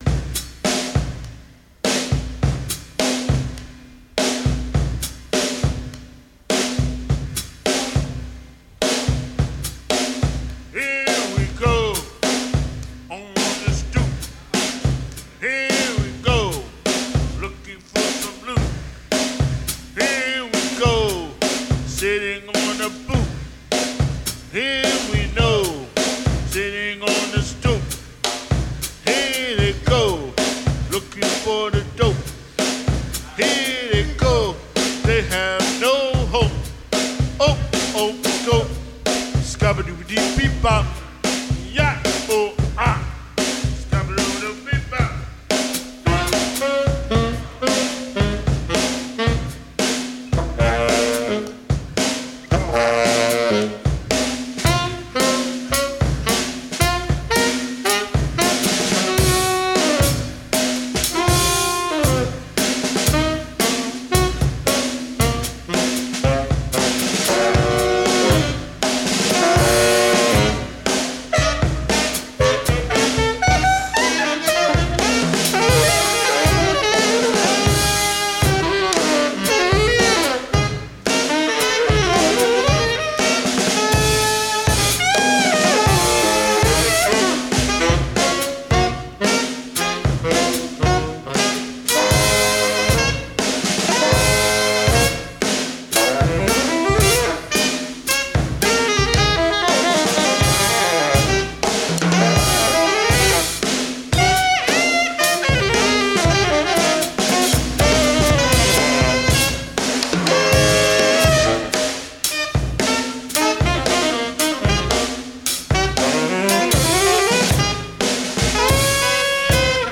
Recorded live at the 39th Street loft, Brooklyn
drums
alto saxophone, electronics
tenor saxophone
Stereo (Metric Halo / ProTools)